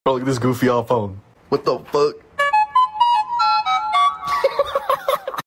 Goffy Ahh Sound Effect Free Download